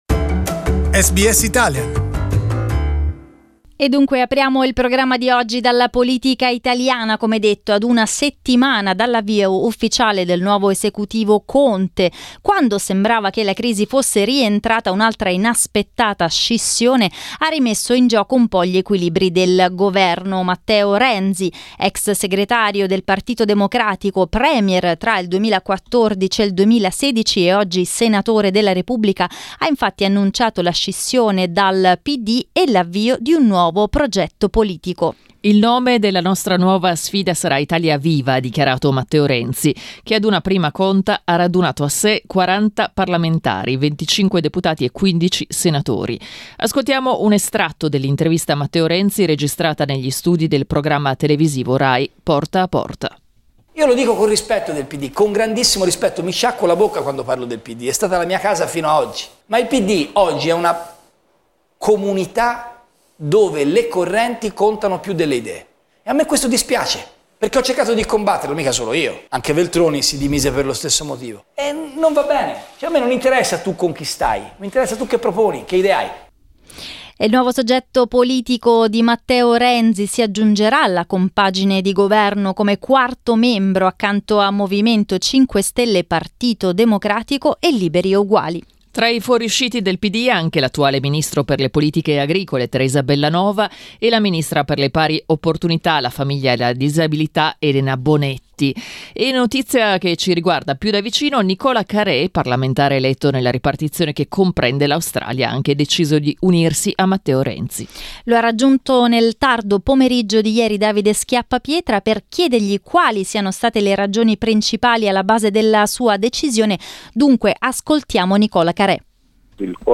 Ai microfoni di SBS Italian , Nicola Carè ha spiegato le ragioni della sua scelta, parlando di una sua formazione di base più orientata verso il centro-sinistra pittosto che verso la sinistra e raccontando come conosca e stimi Matteo Renzi da tempo.